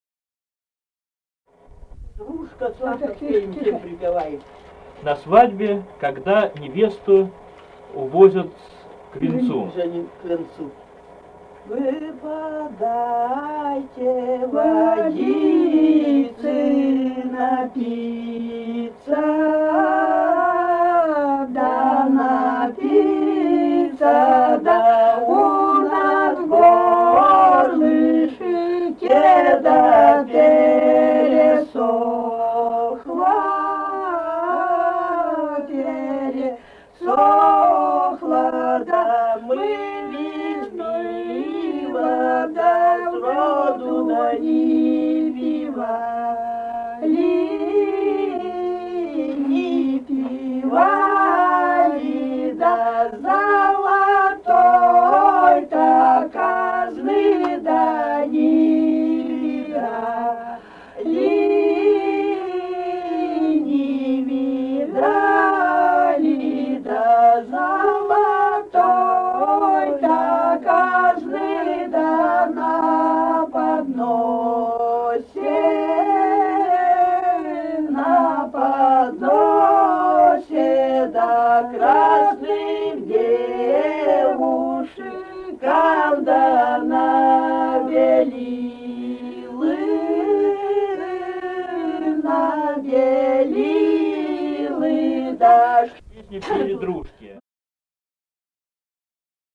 | filedescription = «Вы подайте водицы напиться», свадебная, когда невесту увозят к венцу.
Республика Казахстан, Восточно-Казахстанская обл., Катон-Карагайский р-н, с. Урыль (казаки), июль 1978.